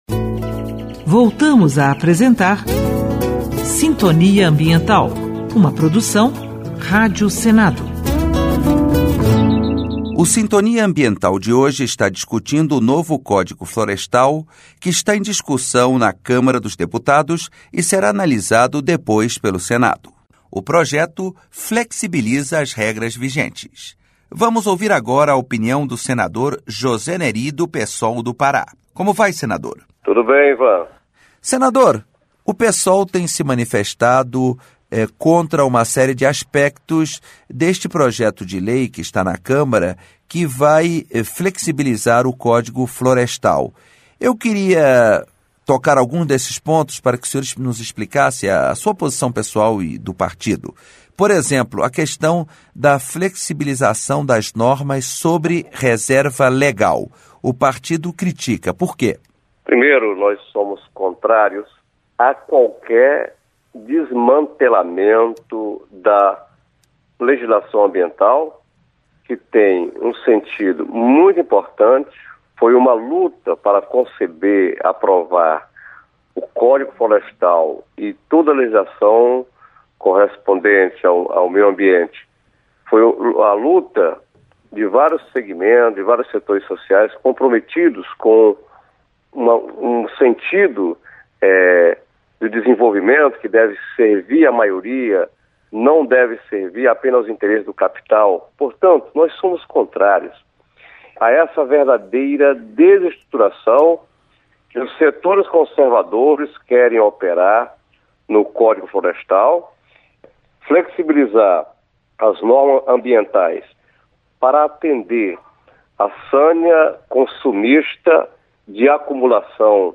Entrevista com a senadora Niúra Demarchi (PSDB-SC) e com o senador Mozarildo Cavalcanti (PTB-RR). E na segunda parte do programa, entrevista com o senador José Nery (PSOL-PA).